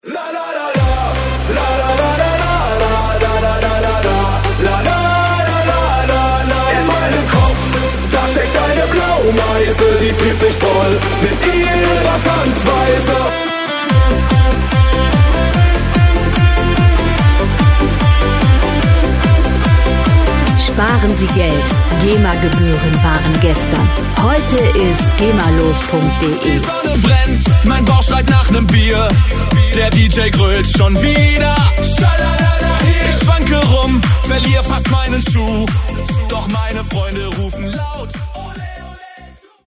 Gema-freie Schlager Pop Musik
Musikstil: Partyschlager
Tempo: 146 bpm
Tonart: C-Dur
Charakter: lustig, spaßig
Instrumentierung: Sänger, Synthesizer, E-Gitarre